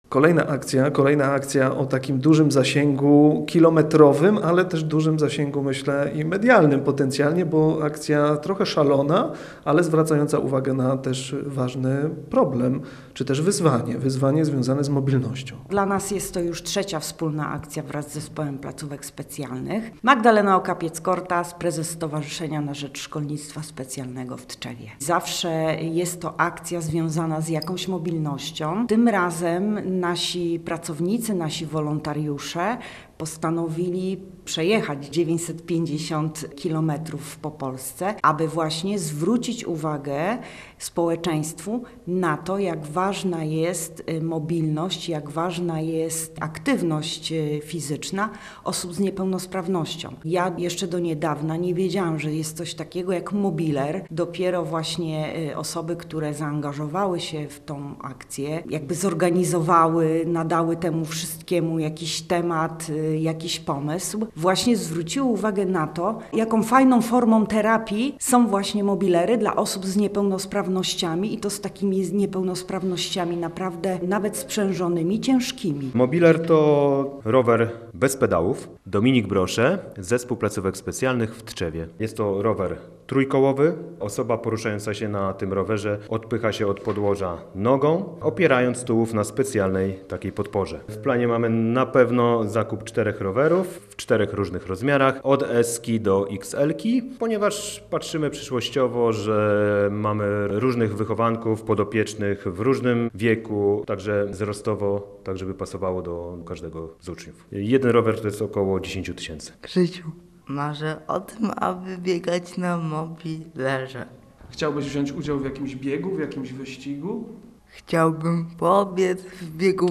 Posłuchaj materiału naszego reportera o akcji „Biegojazda po mobilery”: https